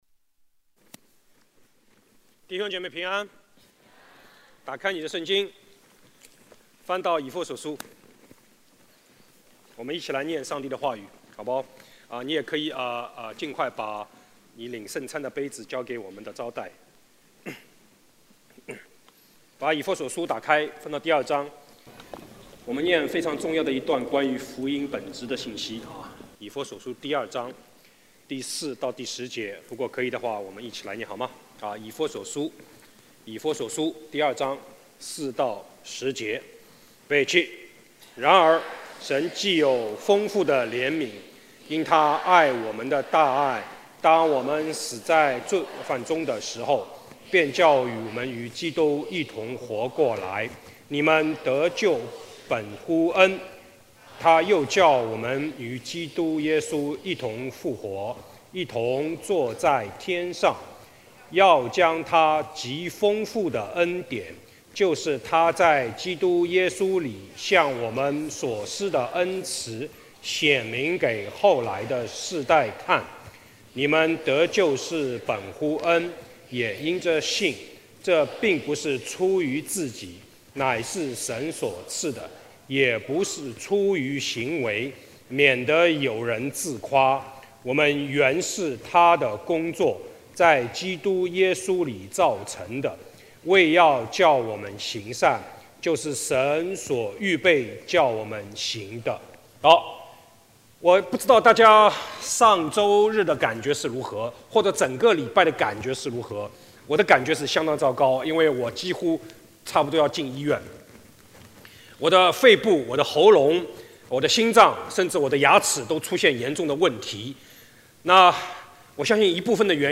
主日证道 | 恩典